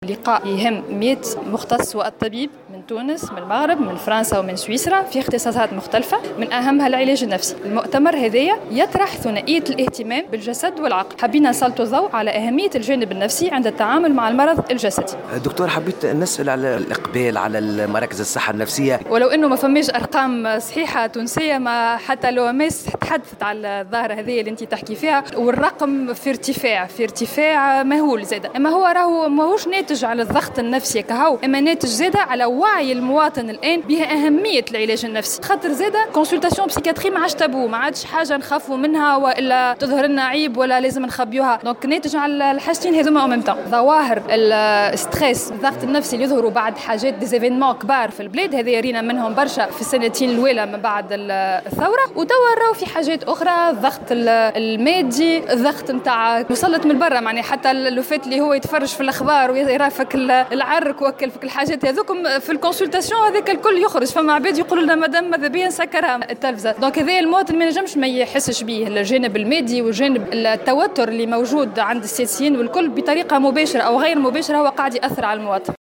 ولاحظت في تصريح لموفد "الجوهرة أف أم" على هامش مؤتمر طبي دولي حول الأمراض النفسية انتظم اليوم السبت في سوسة، أنه عادة ما يتم تسجيل الضغوطات النفسية اثر حدوث تحولات مهمة مثلما حصل في تونس مباشرة بعد الثورة.